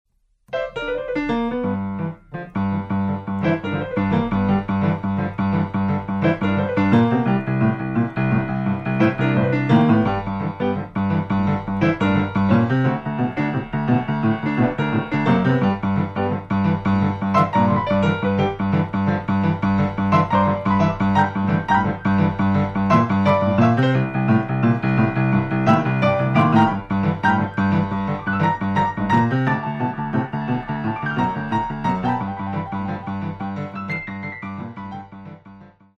Style: Jump Blues Piano